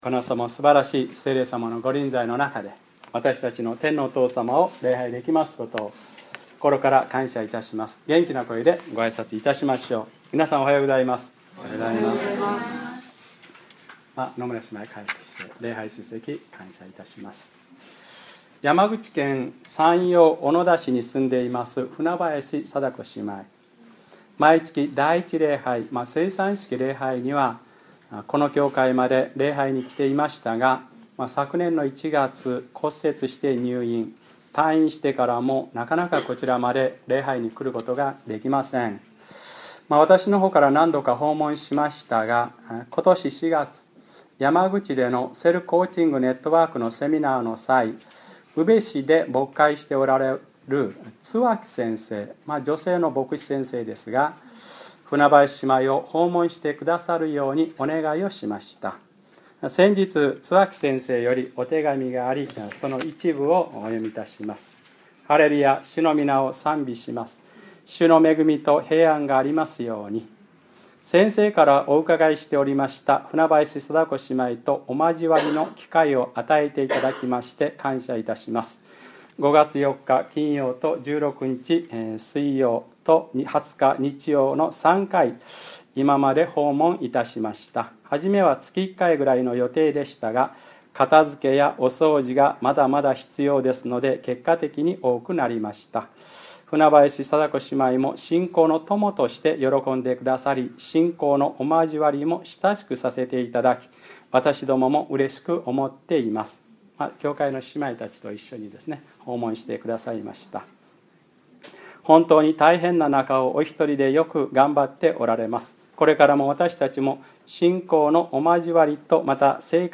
2012年6月10日(日）礼拝説教 『ルカ9/ ルカ4章1節～』